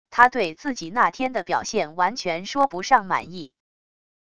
她对自己那天的表现完全说不上满意wav音频生成系统WAV Audio Player